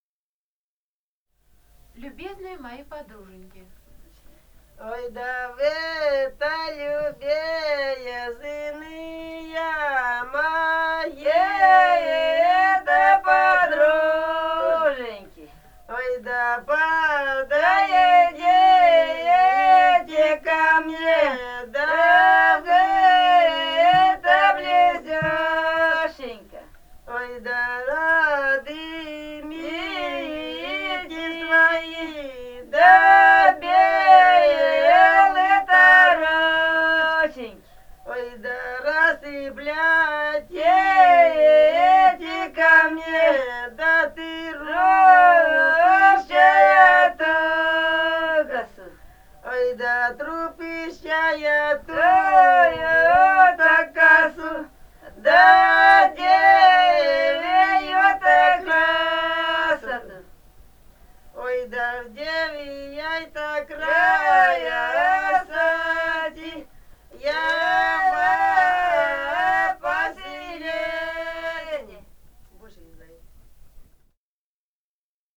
полевые материалы
Алтайский край, с. Маральи Рожки Чарышского района, 1967 г. И1002-05